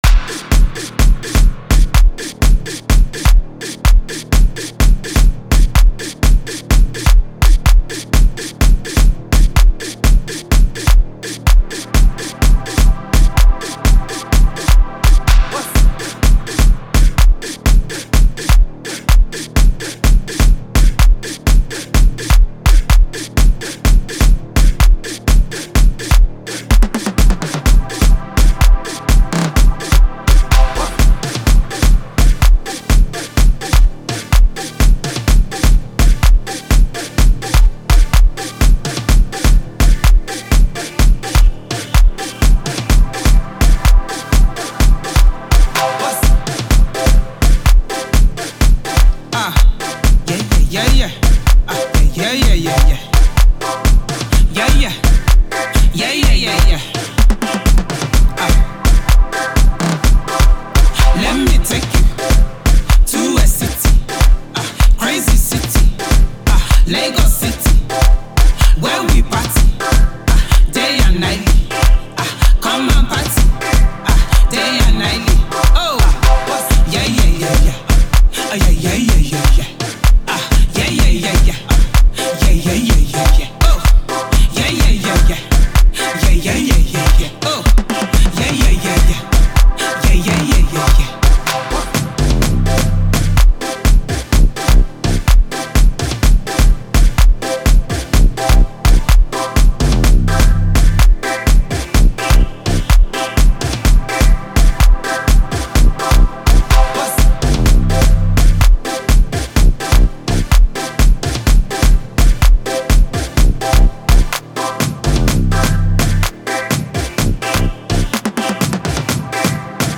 club banger
Gqom track